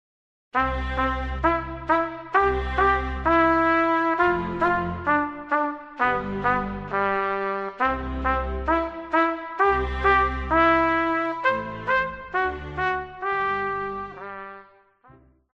Classical
Orchestra
Instrumental
Only backing